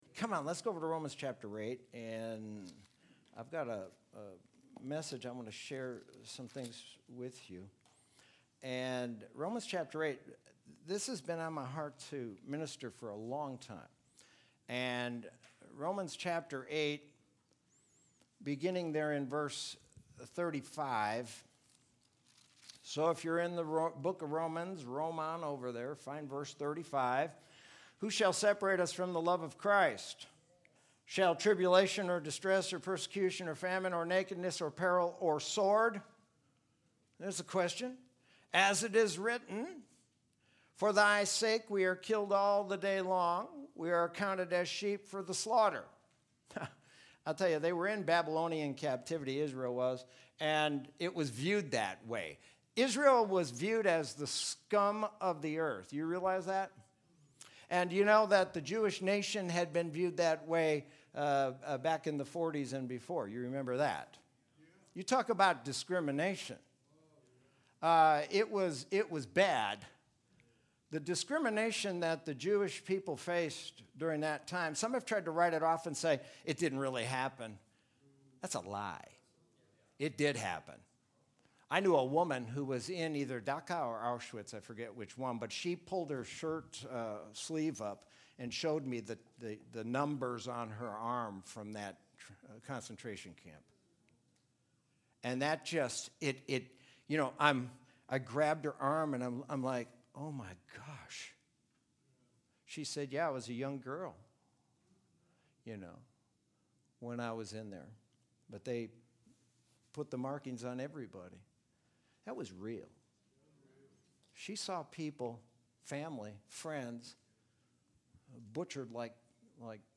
Sermon from Sunday, July 19, 2020.